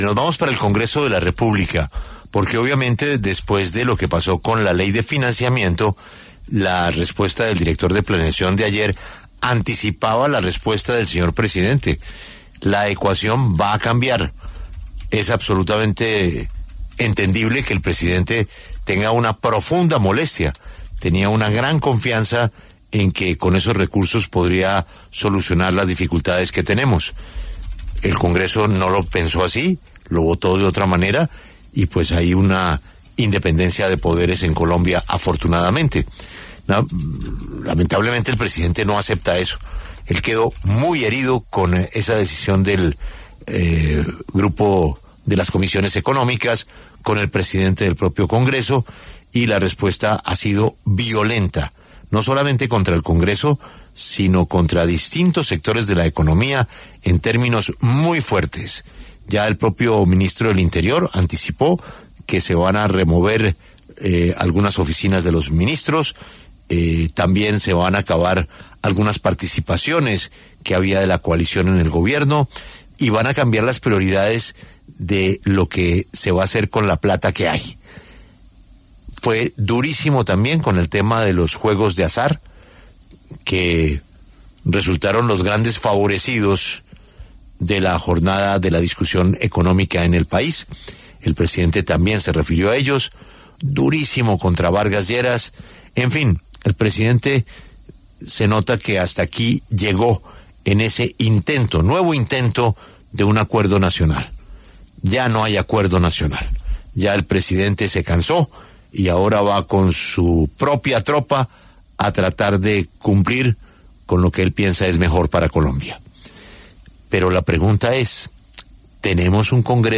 En entrevista en La W, el representante Eduard Sarmiento, del Pacto Histórico, y la representante Erika Sánchez, de la bancada de oposición, se refirieron a la reciente designación de Daniel Mendoza como embajador en Tailandia.